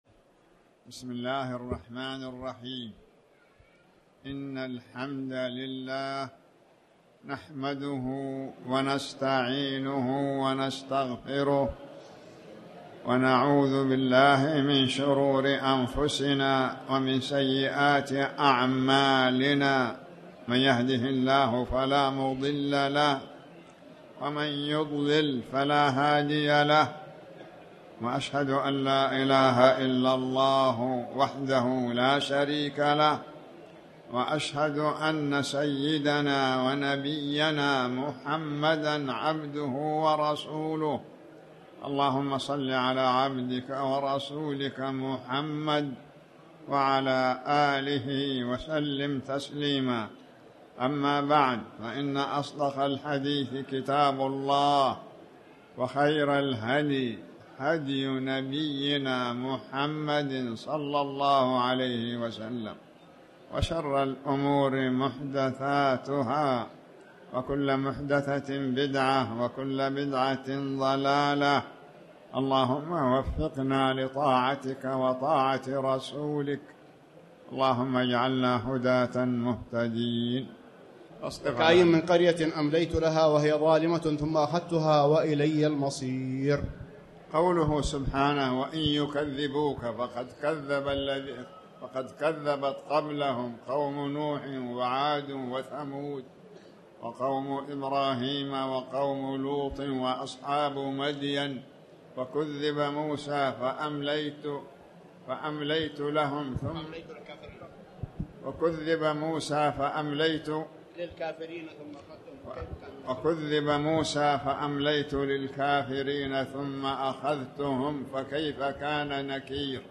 تاريخ النشر ٤ رجب ١٤٣٩ هـ المكان: المسجد الحرام الشيخ